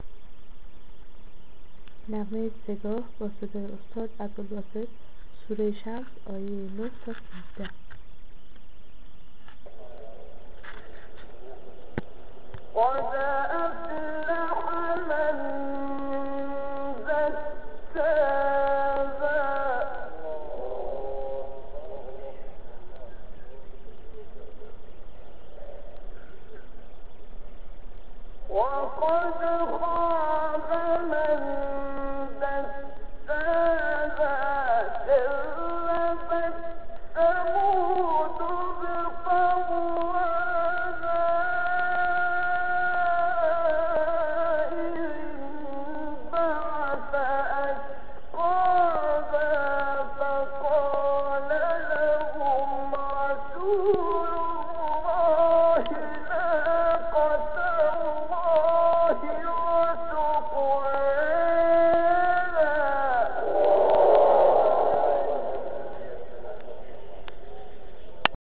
سایت-قرآن-کلام-نورانی-سه-گاه-عبدالباسط-سوره-شمس-آیه-9.mp3